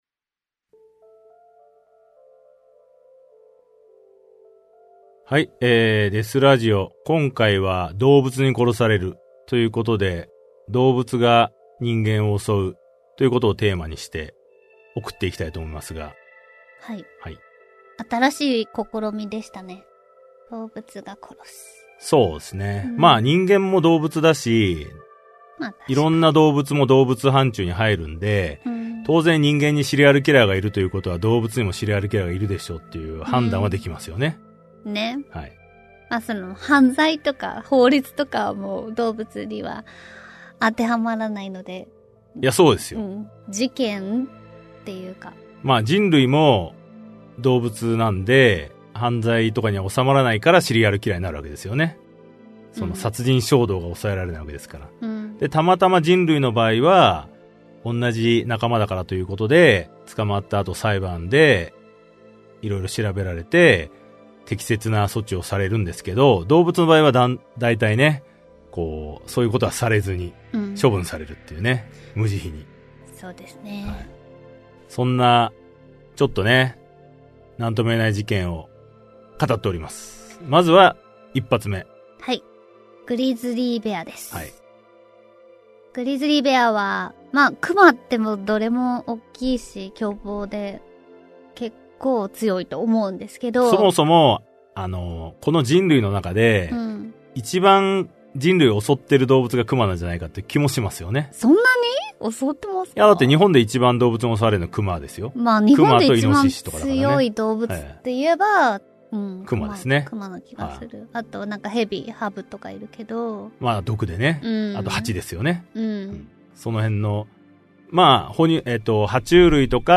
[オーディオブック] トゥルークライム デスラジオセレクション Vol.22 動物に殺される